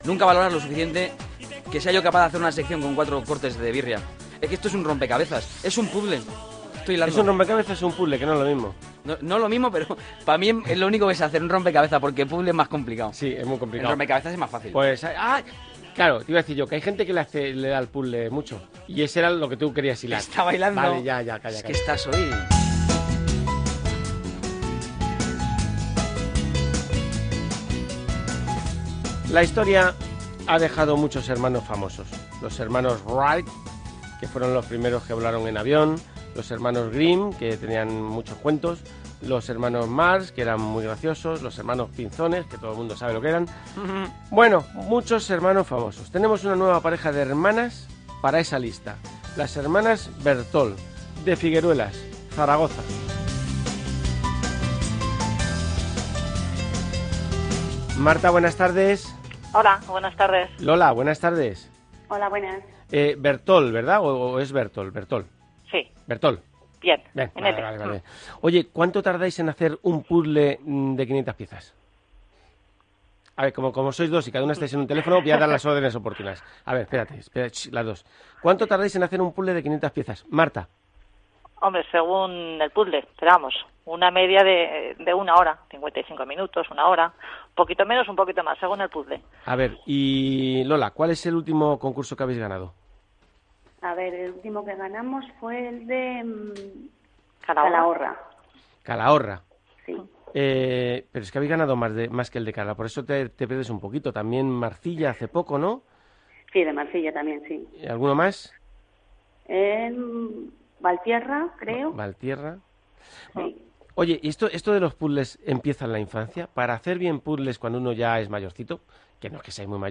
Pero que majas son estas chicas, da igual por la radio , que en persona :mrgreen: Muy bien la entrevista , muy naturales y simpaticas, como siempre .